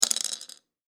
サイコロ・ダイス | 無料 BGM・効果音のフリー音源素材 | Springin’ Sound Stock
漆器でチンチロリン1.mp3